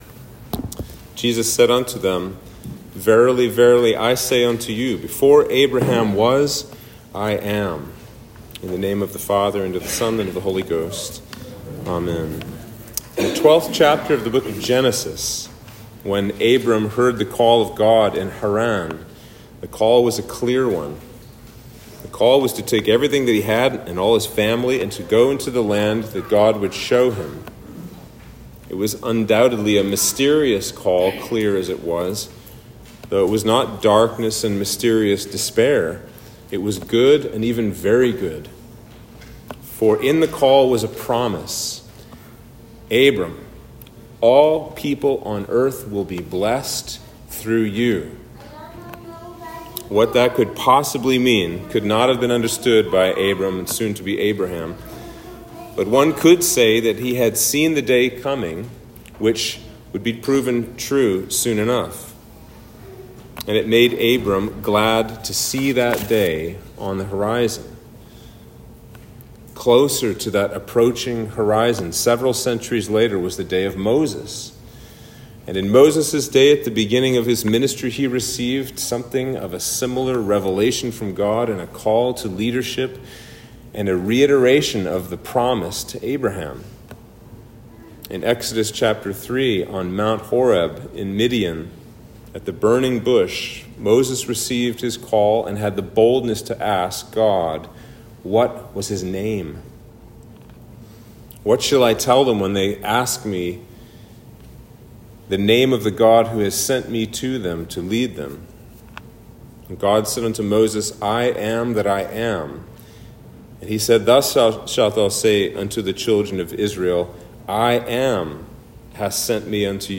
Sermon for Lent 5 - Passion Sunday